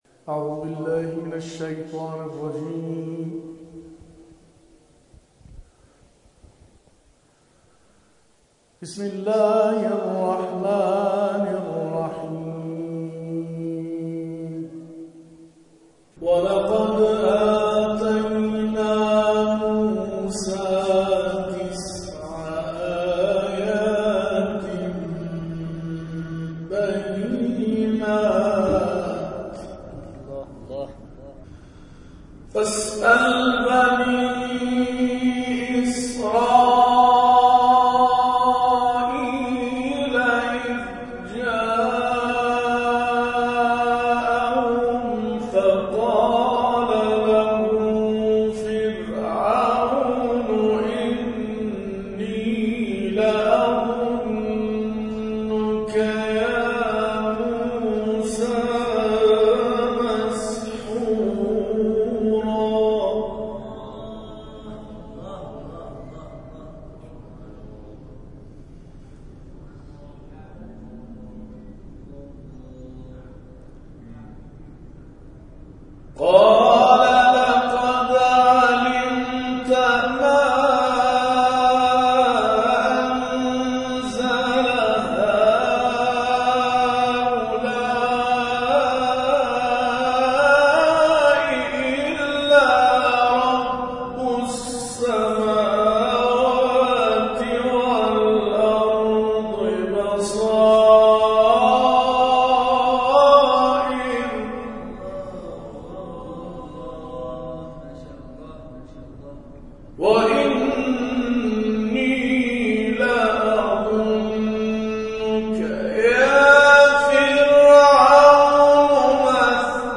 گروه جلسات و محافل: اولین جلسه قرآن «احمد ابوالقاسمی»، قاری بین‌المللی، ساعتی پس از ابلاغ حکم ریاست رادیو قرآن در مسجد محمد رسول‌الله(ص) با حضور قرآن‌آموزانی از سنین مختلف برگزار شد.
احمد ابوالقاسمی در ابتدا به تلاوت آیاتی از سوره مبارکه کهف پرداخت و مورد تشویق حاضران در جلسه قرار گرفت.
در ادامه تلاوت احمد ابوالقاسمی ارائه می‌شود.